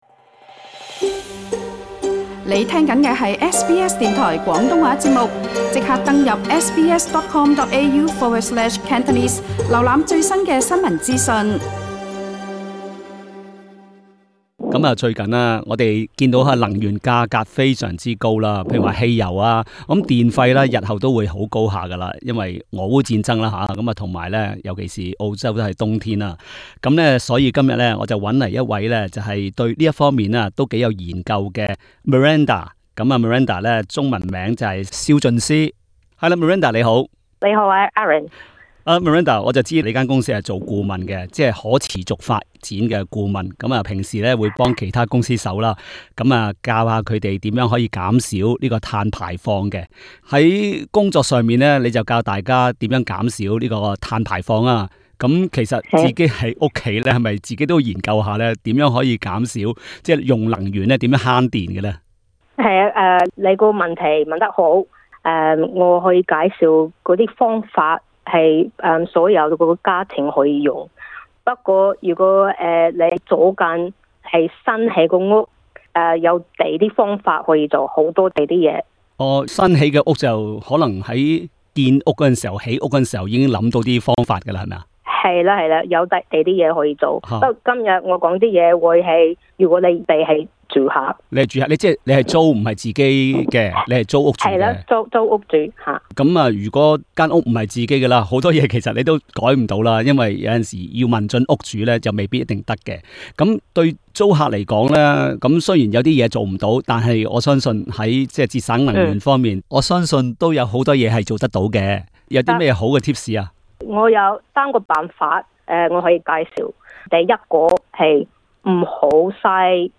【社区专访】